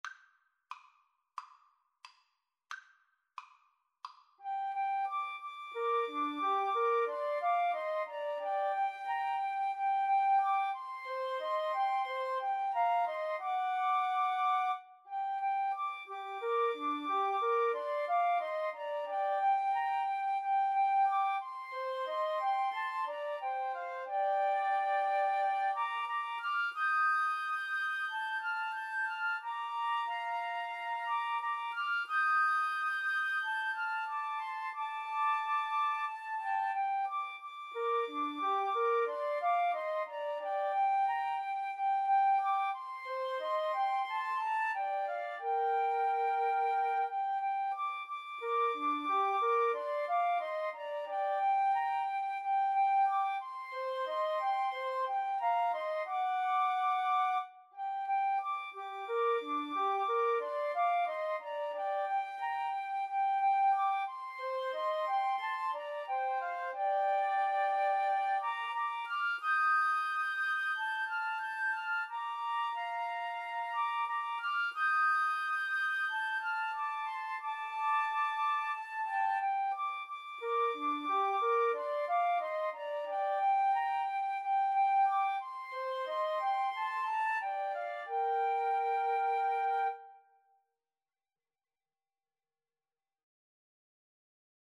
4/4 (View more 4/4 Music)
Andante = c. 90